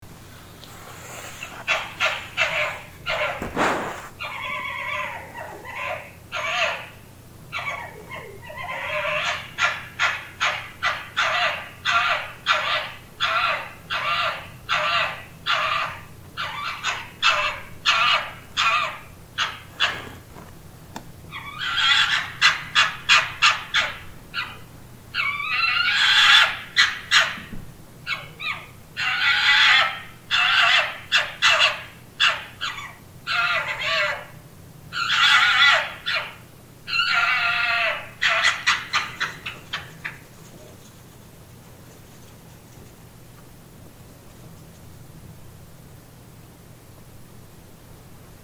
Urban Fox Call Sound Button - Free Download & Play